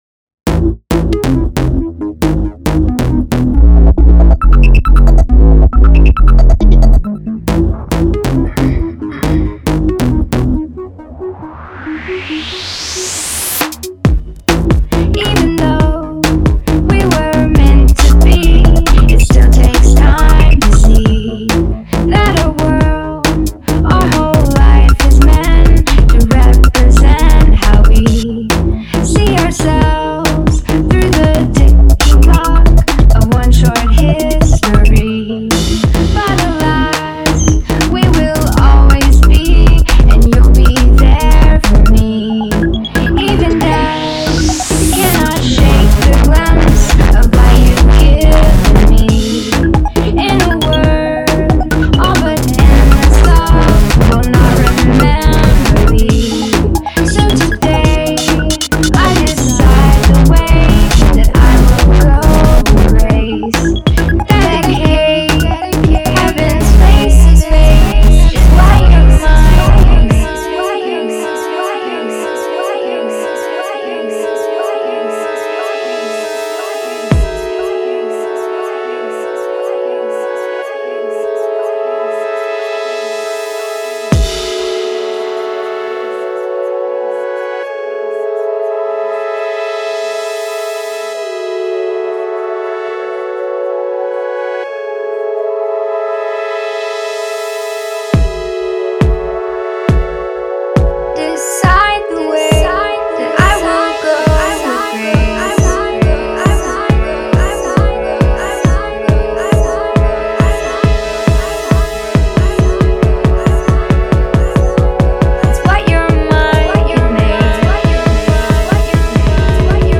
an interesting mix of indie and electronic music